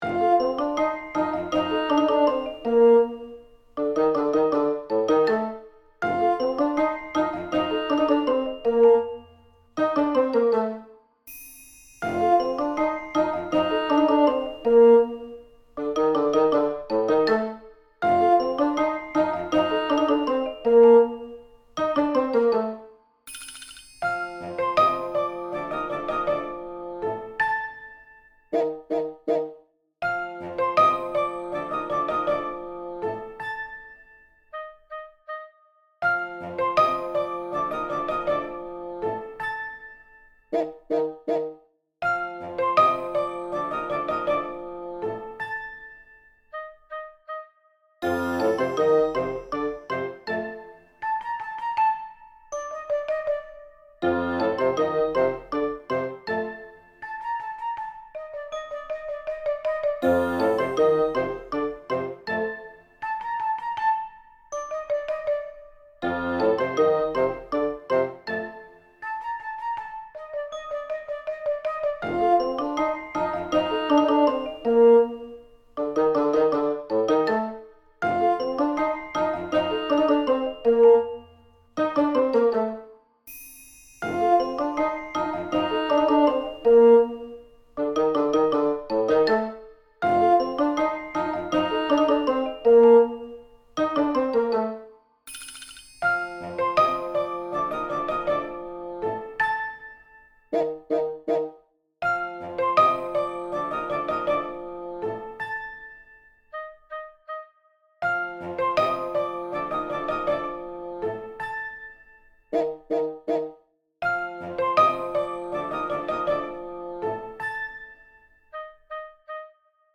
気だるげなゆるい日常系BGM。